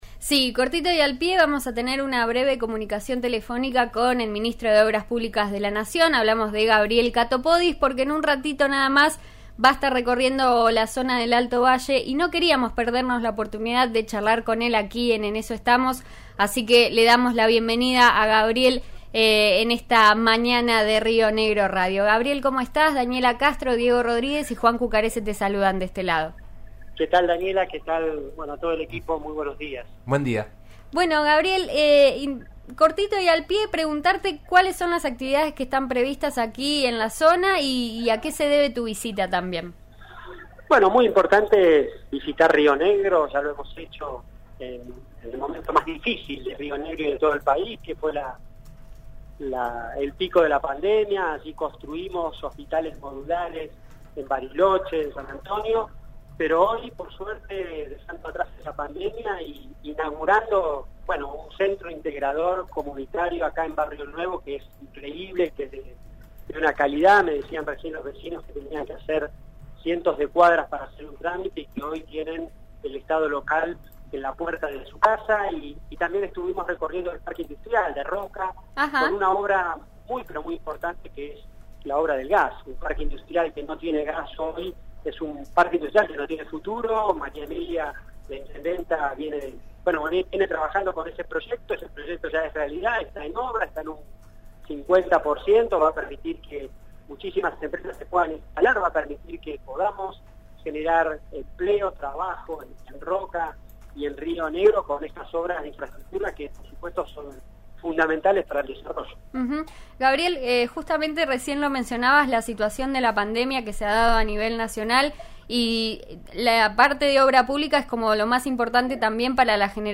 El ministro de Obras Públicas dialogó esta mañana con el programa 'En Eso Estamos'.